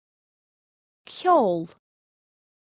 Amazon AWS (pronunciation):